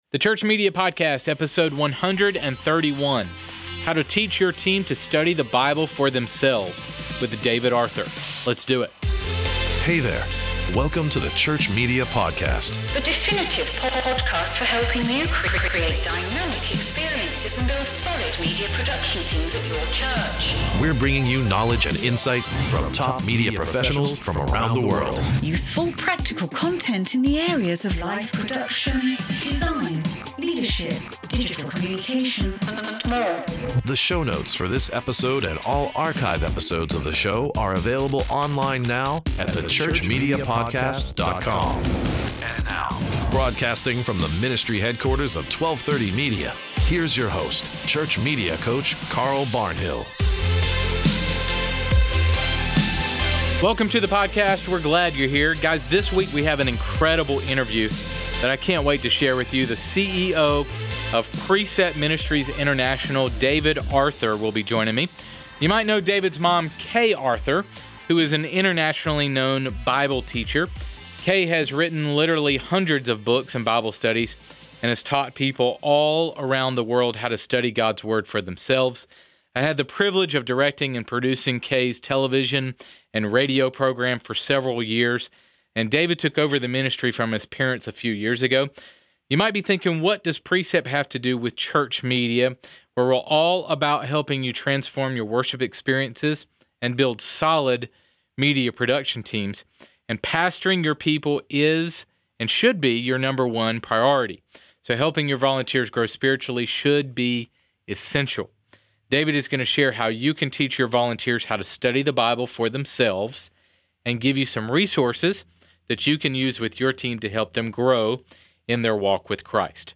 This week we have an incredible interview that we can’t wait to share with you.